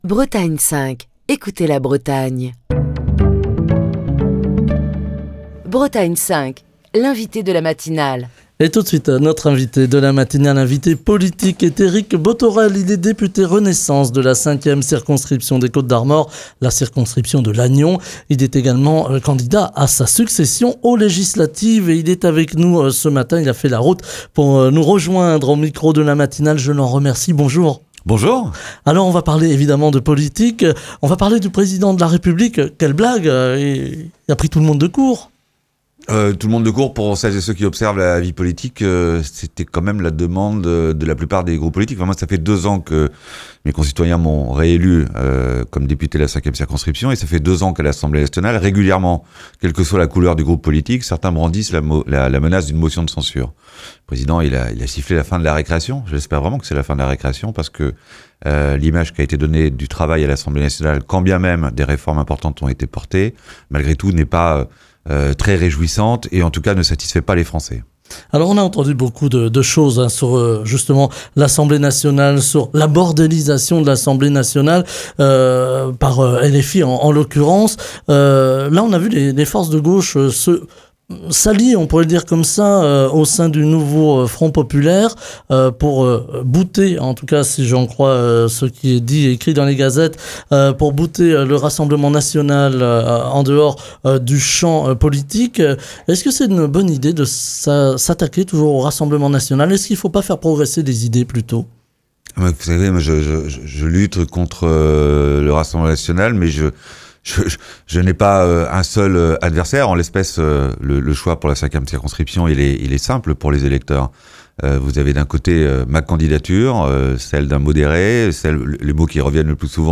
Ce matin, nous accueillons Éric Bothorel, député Renaissance et candidat à sa réélection dans la 5ème circonscription des Côtes d'Armor (Lannion), dans la matinale de Bretagne 5. Éric Bothorel dresse un bilan détaillé de son action politique et présente les grandes lignes du programme de la majorité présidentielle.